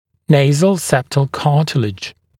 [‘neɪzl ˈseptl ‘kɑːtɪlɪʤ][‘нэйзл ˈсэптл ‘ка:тилидж]носовой перегородочный хрящ